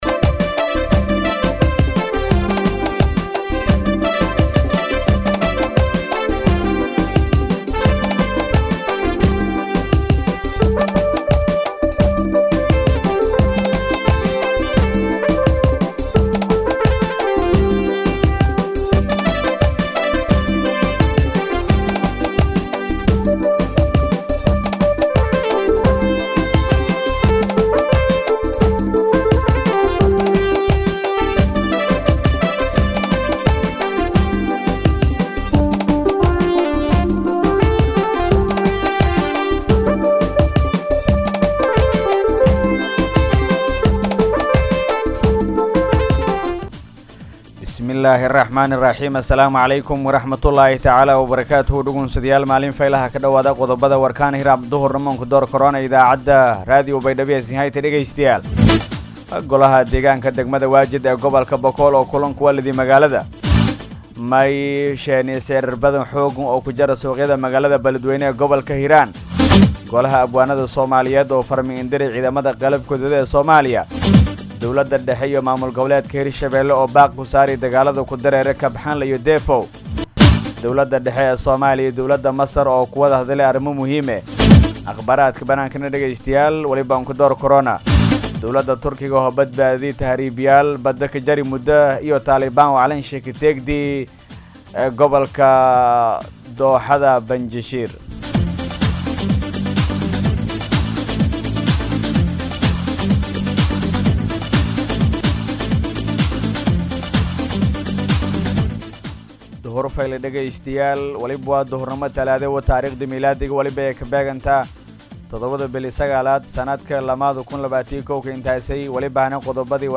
DHAGEYSO:-Warka Duhurnimo Radio Baidoa 7-9-2021
BAYDHABO–BMC:–Dhageystayaasha Radio Baidoa ee ku xiran Website-ka Idaacada Waxaan halkaan ugu soo gudbineynaa Warka ka baxay Radio Baidoa.